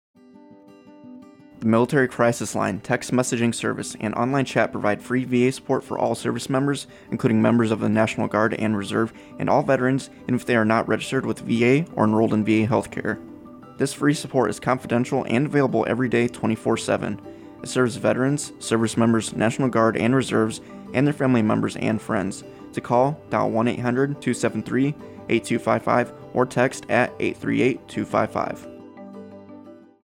Thirty-second radio spot highlighting the Crisis Line to be played on AFN Bahrain's morning and afternoon radio show.
Radio Spot